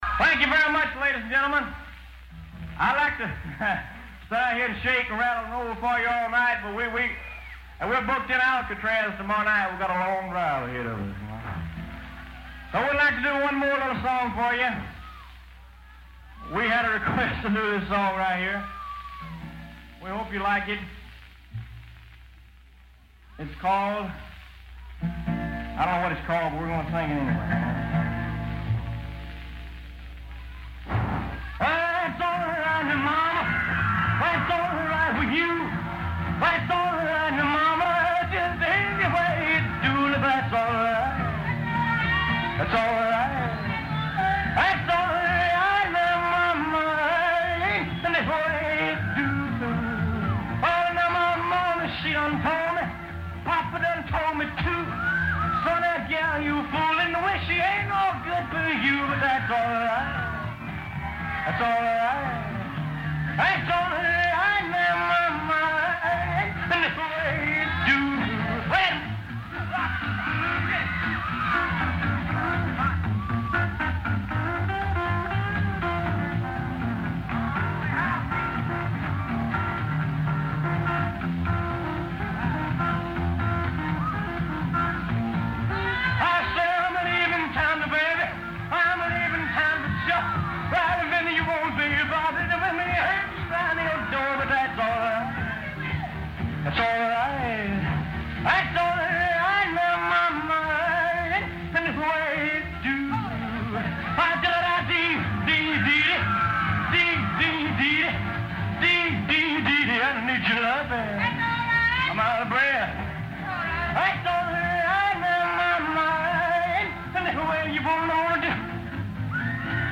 First known live recording of "The King".